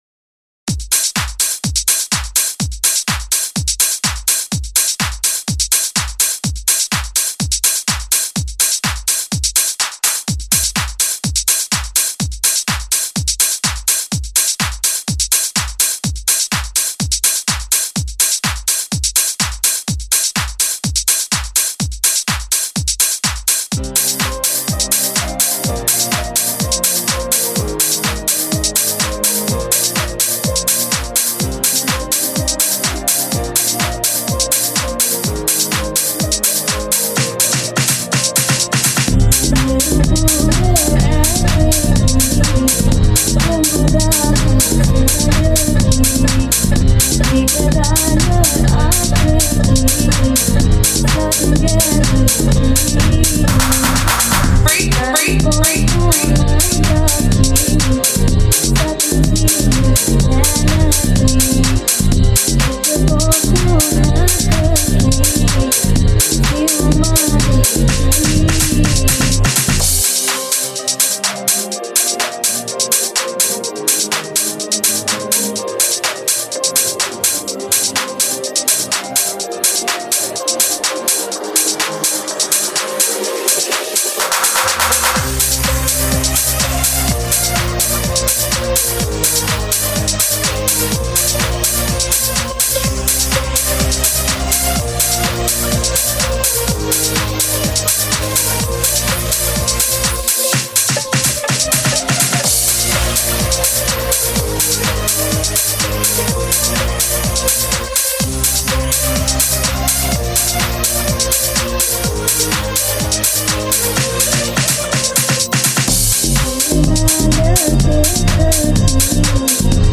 Tech House Mix